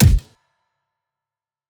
pause-continue-click.wav